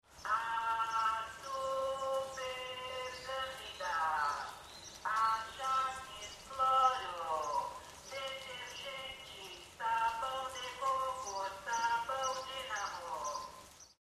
Il y a de la musique partout ici (l’accent brésilien, les oiseaux,..) le matin un camion passe pour vendre des produits d’entretien, j’enregistre son passage.